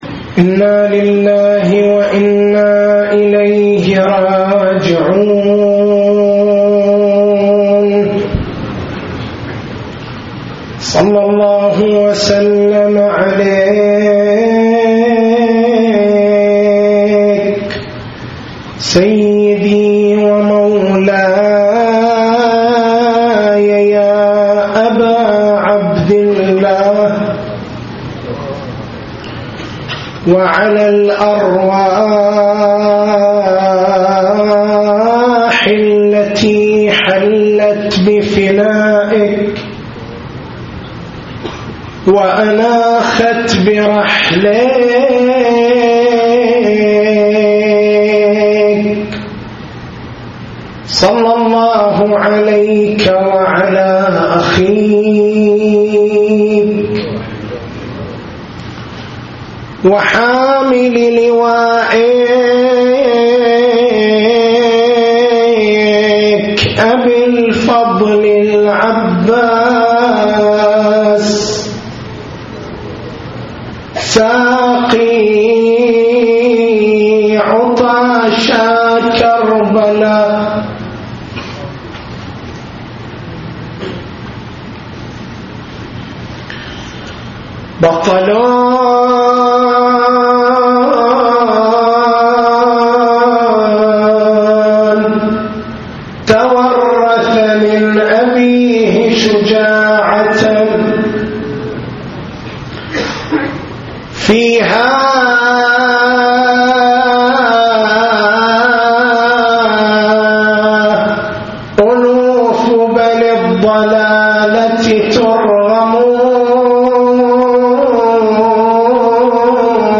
تاريخ المحاضرة: 07/01/1433 نقاط البحث: بيان المقصود من المنصب الإلهي الفرق بين المنصب الإلهي والمنصب الوضعي هل الإمامة جعلٌ إلهيٌ، أم هي جعلٌ بشريٌّ؟